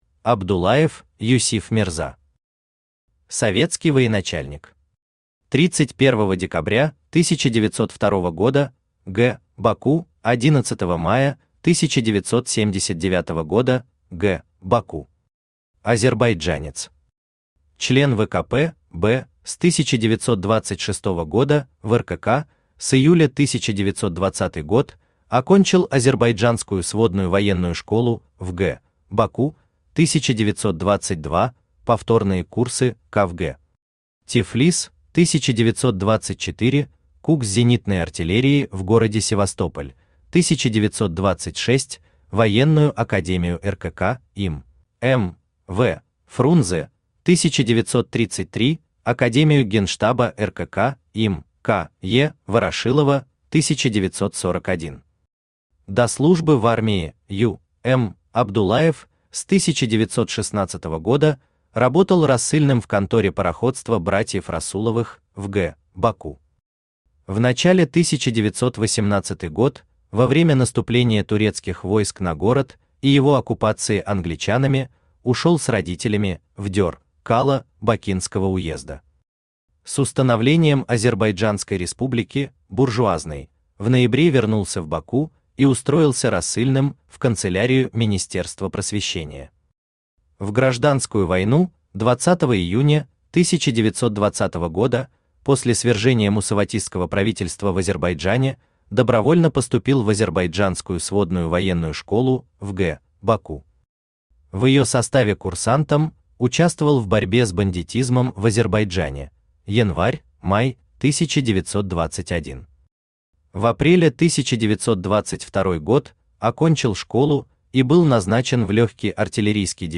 Аудиокнига Кавалеры ордена Кутузова II степени. Том 1 | Библиотека аудиокниг